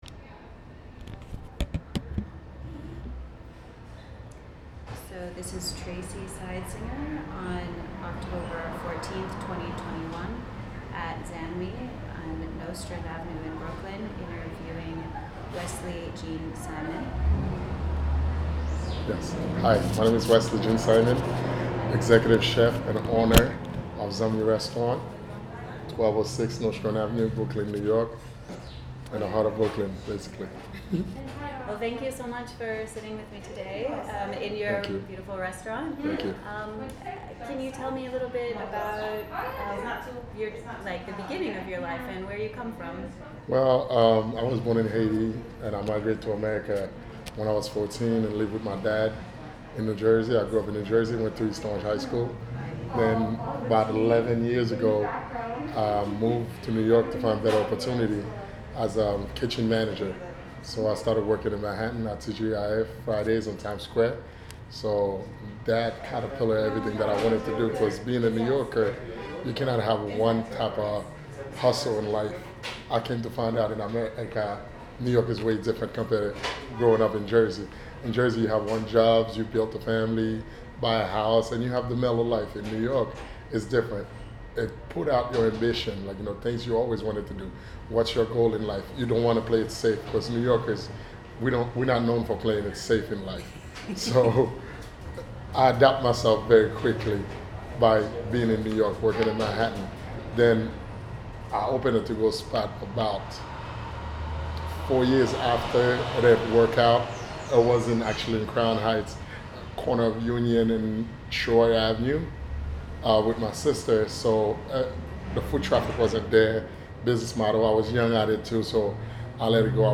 Listen: Oral History Interviews - Voices of Lefferts